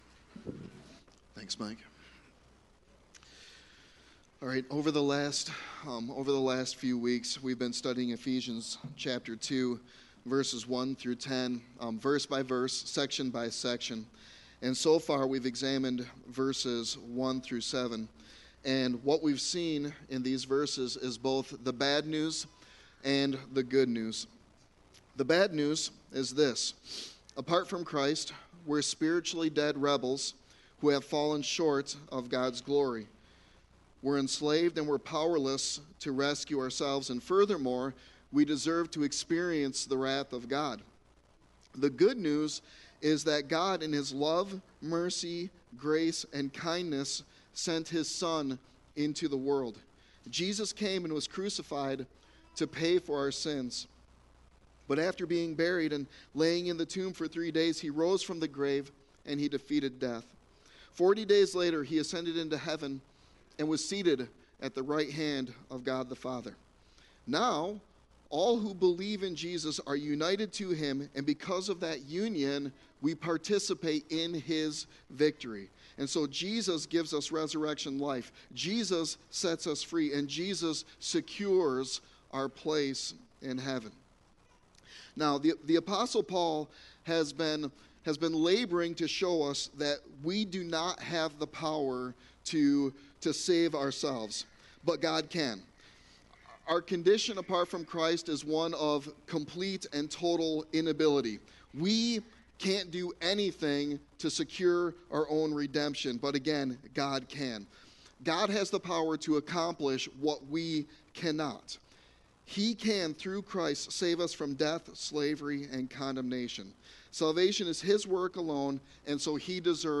Sermon Text: Ephesians 2:8-10